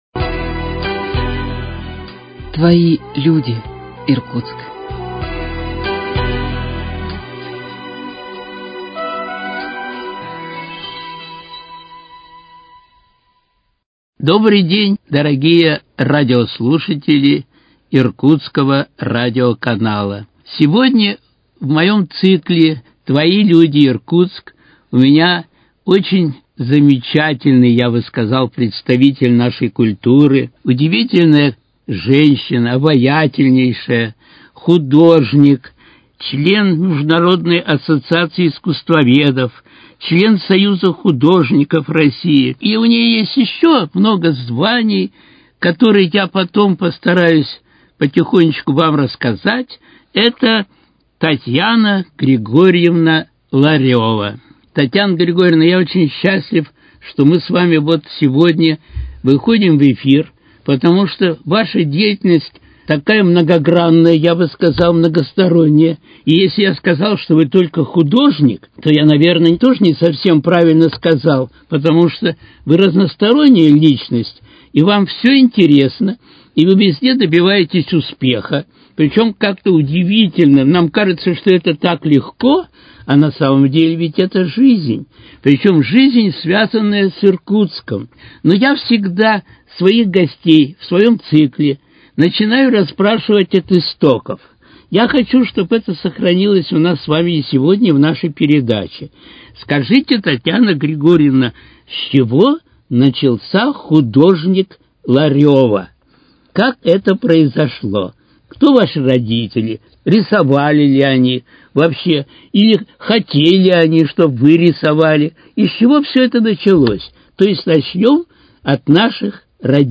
Твои люди, Иркутск: Беседа